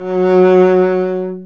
Index of /90_sSampleCDs/Roland L-CD702/VOL-1/STR_Cb Bowed/STR_Cb1 mf vb